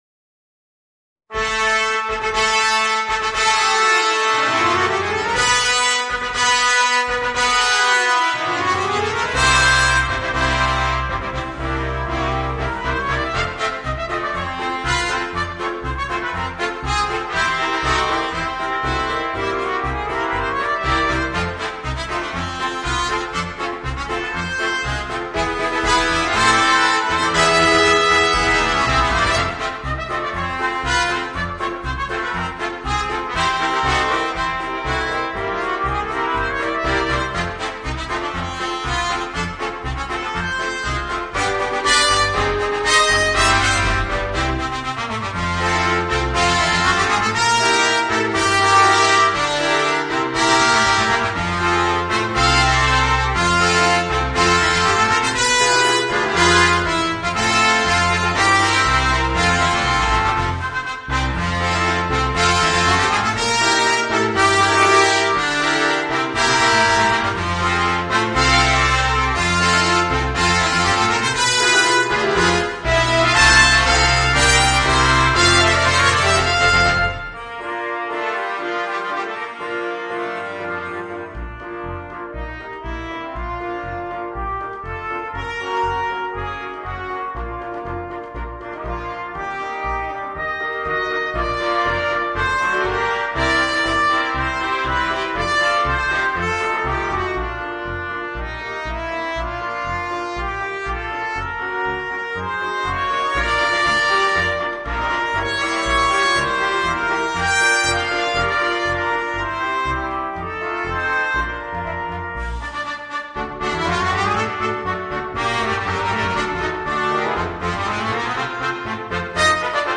Voicing: 5 Trumpets and Piano